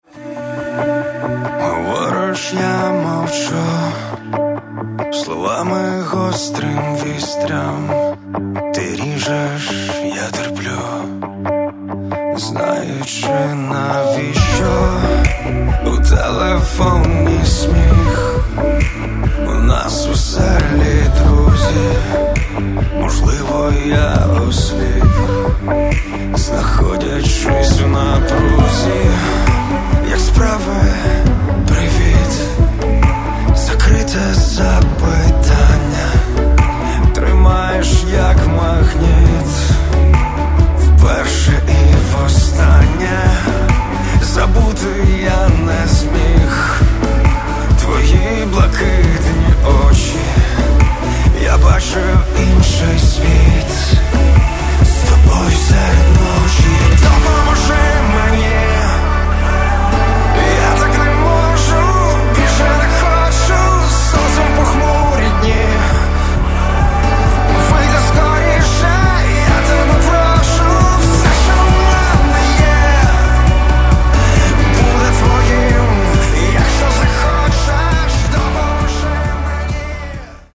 Catalogue -> Rock & Alternative -> Light Rock